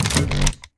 laser-turret-activate-02.ogg